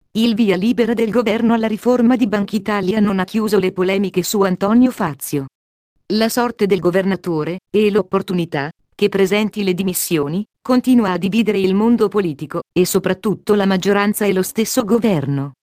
Texte de d�monstration lu par Silvia (Nuance RealSpeak; distribu� sur le site de Nextup Technology; femme; italien)